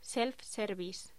Locución: Self-service
Sonidos: Voz humana
Sonidos: Hostelería